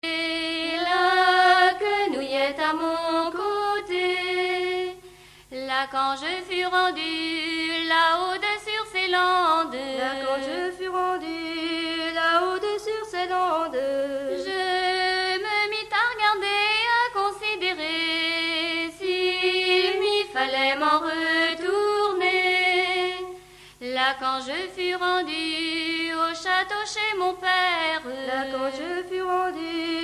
Musique : Traditionnel
Origine : Bretagne
cercle celtique de loudeac - chansons de l oust - vol 3 - 30-nous etions cinq enfants (chant a pause) (face b).mp3